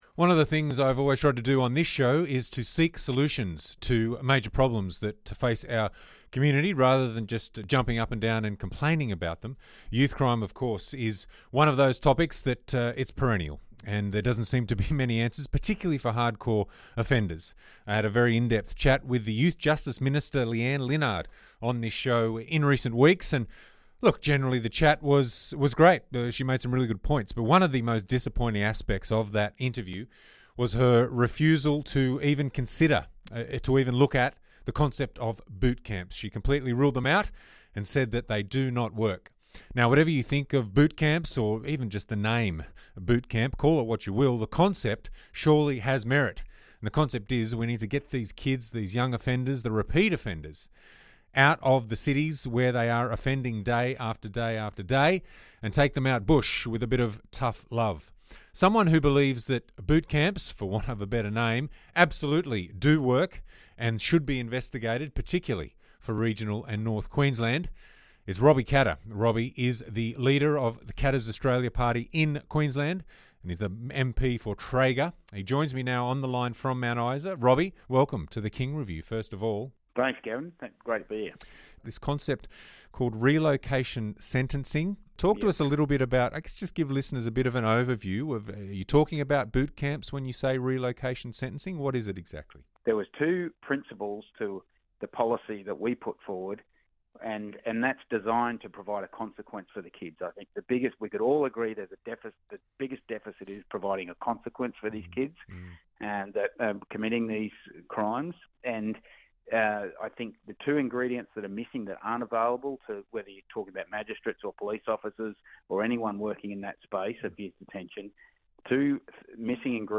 The King Review - Gavin chats with state leader of Katter’s Australian Party Robbie Katter about his proposal to relocate young offenders out bush for rehab and reform - 1 April 2022